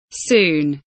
soon kelimesinin anlamı, resimli anlatımı ve sesli okunuşu